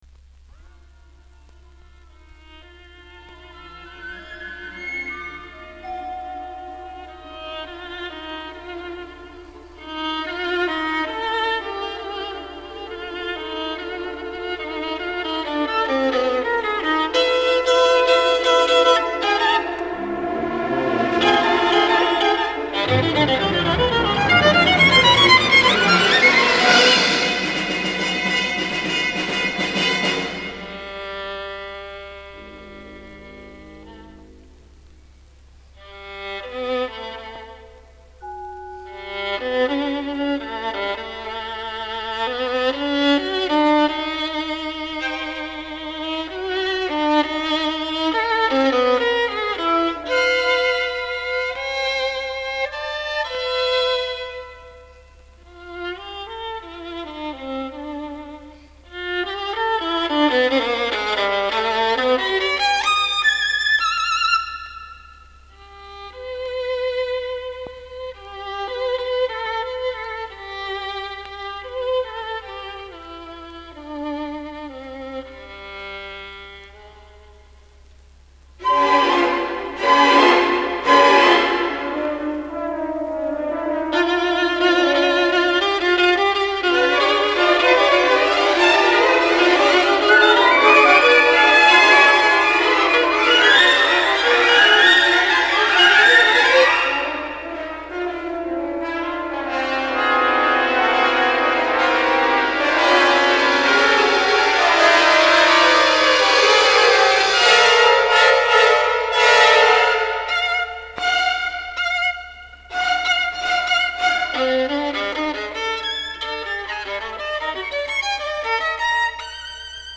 скрипка